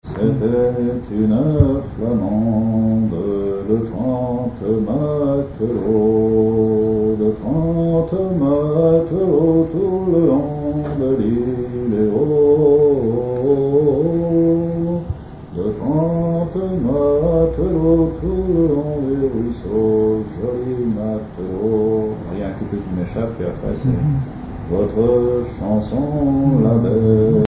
Mémoires et Patrimoines vivants - RaddO est une base de données d'archives iconographiques et sonores.
Chansons maritimes
Pièce musicale inédite